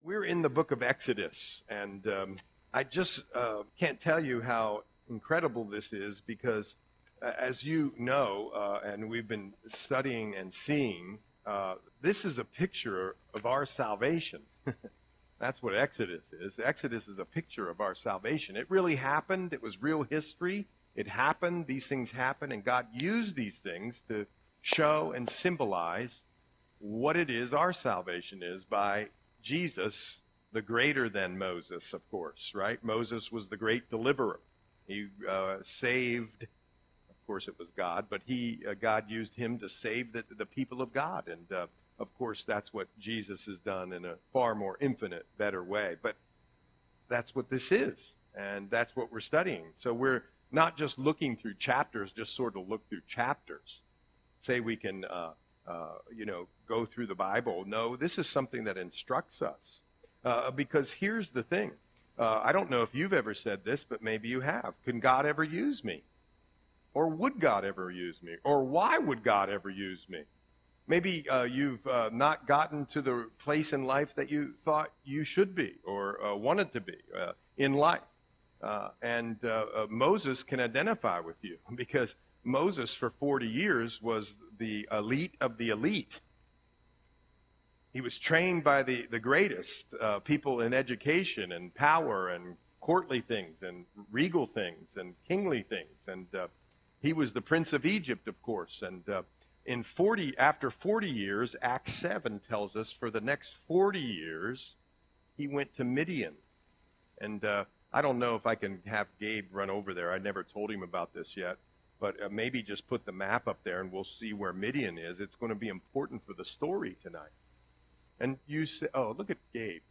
Audio Sermon - October 23, 2024